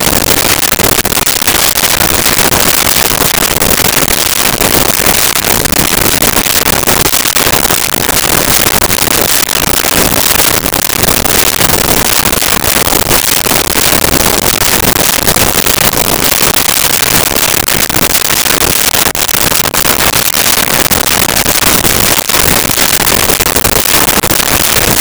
Muscle Car Engine Cooling
Muscle Car Engine Cooling.wav